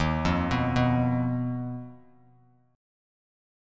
Ebsus2#5 chord